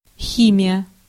Ääntäminen
Ääntäminen US Tuntematon aksentti: IPA : /ˈkɛm.ɪ.stri/